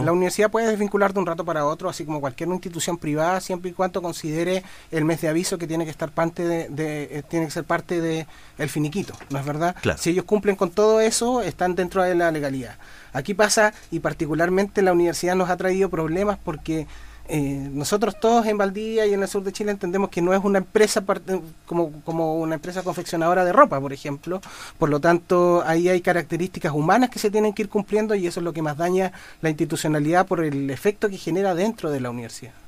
El seremi del Trabajo y Previsión Social en Los Ríos, Rodrigo Leiva, en entrevista con Radio Bío Bío, afirmó que son 23 trabajadoras que quedaron sin empleo, agregando que la universidad, como empresa privada, puede desvincular al personal, siempre y cuando cumpla con el aviso de un mes de anticipación.